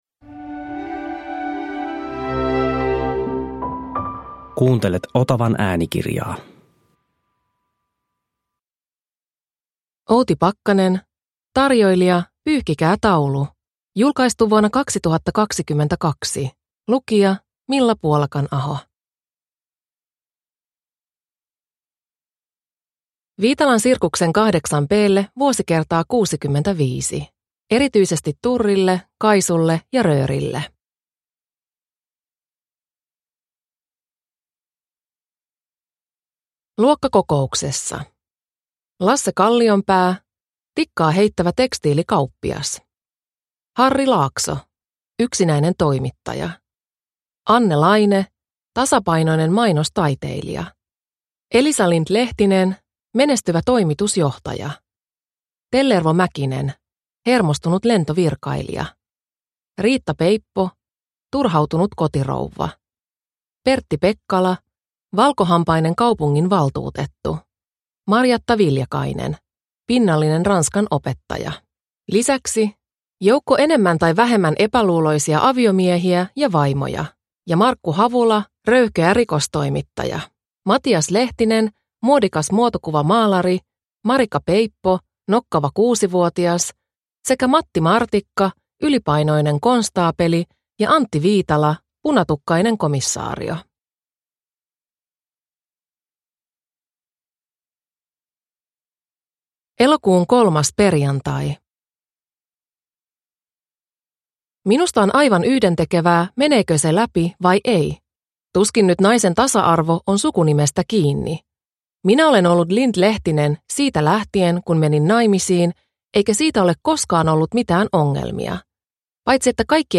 Tarjoilija, pyyhkikää taulu – Ljudbok – Laddas ner